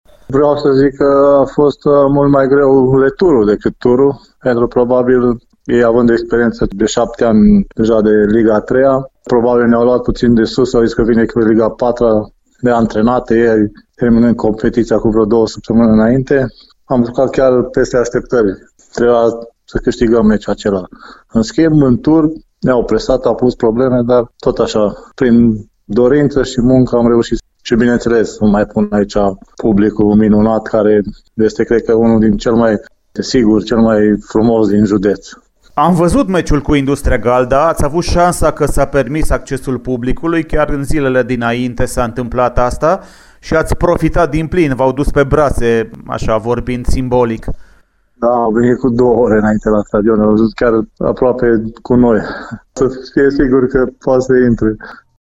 interviului